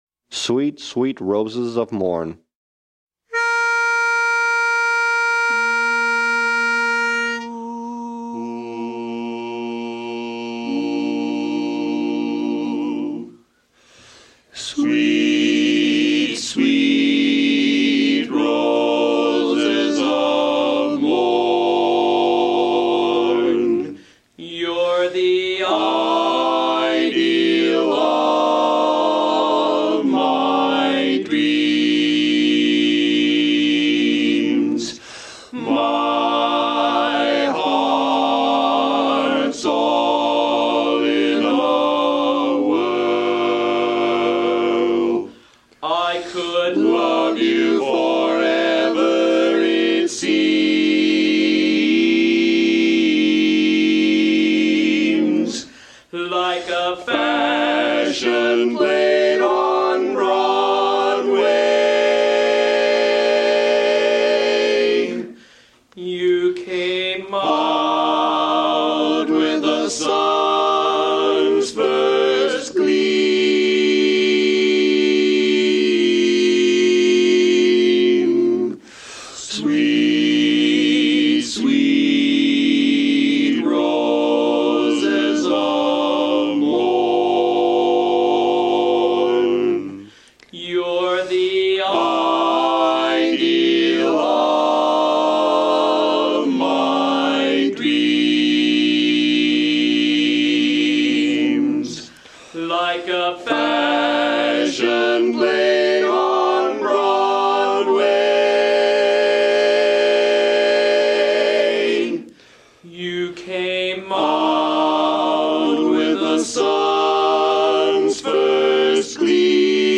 Von 19:15 bis 20:00 Uhr proben die NoNames, unser kleines, aber feines, Männerensemble, am gleichen Ort.
Sweet sweet Roses of Morn Tenor